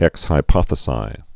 (ĕks hī-pŏthĭ-sī)